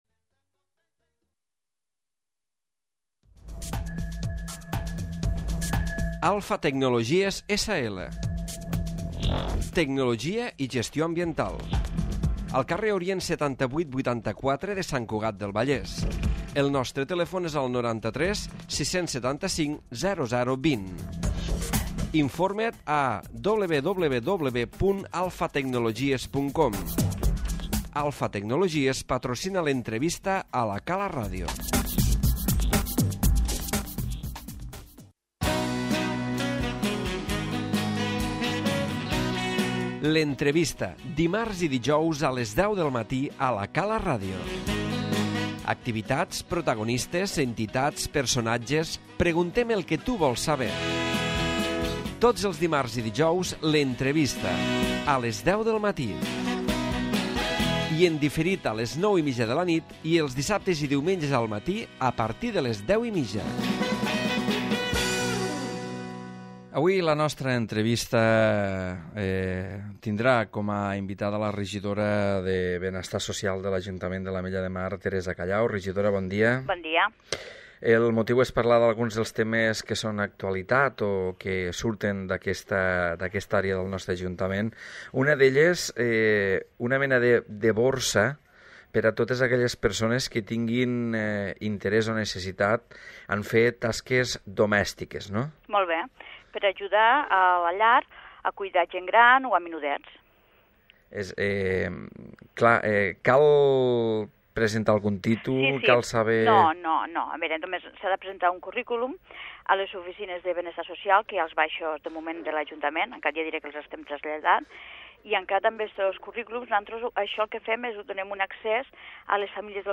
L'Entrevista
Entrevista a Teresa Callau, Regidora de Benestar Social, que ha parlat sobre el servei d'Acollida a Immigrants que es du a terme a càrrec de dos treballadores de l'àrea municipal.